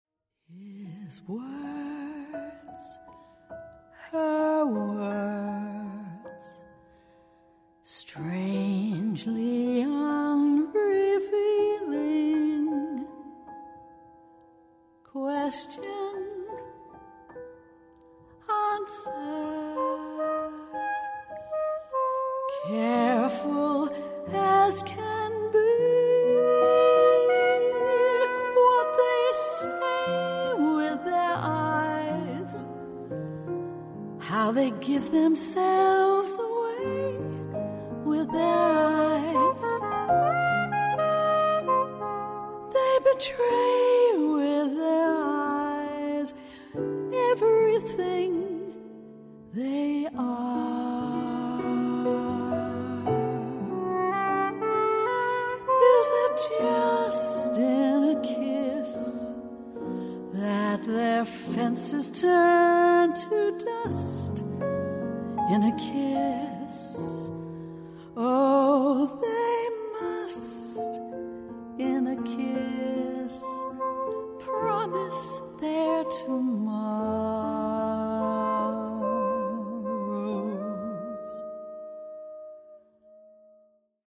Recorded March 2017, ArteSuono Studio, Udine